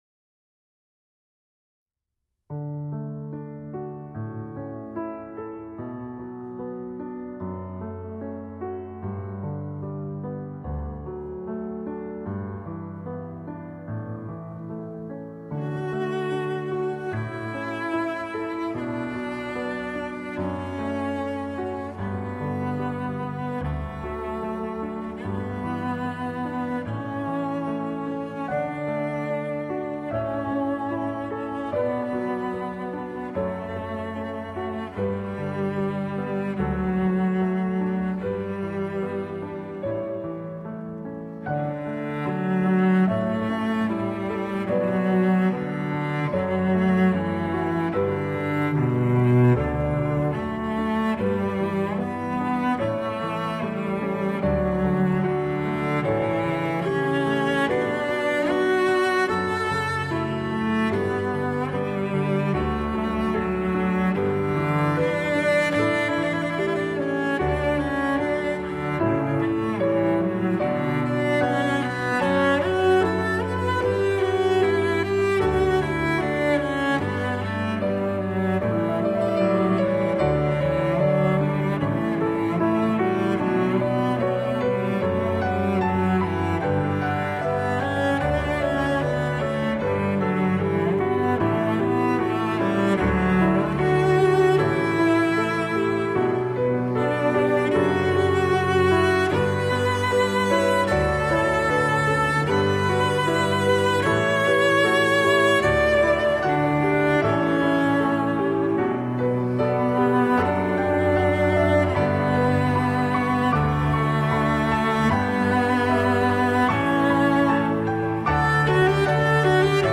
피아노